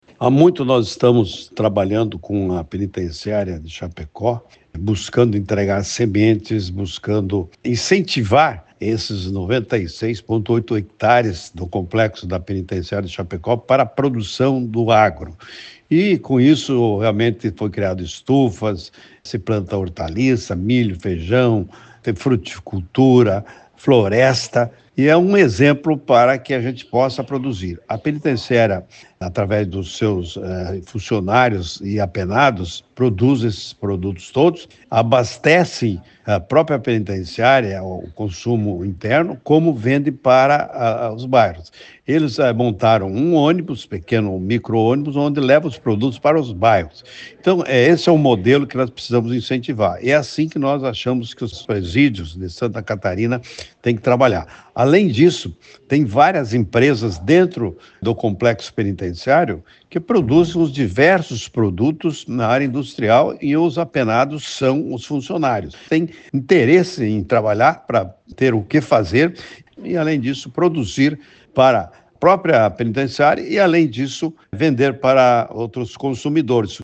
O secretário destacou esse potencial de produção, que pode ser exemplo para todos os presídios do Estado:
SECOM-Sonora-secretario-da-Agricultura-3.mp3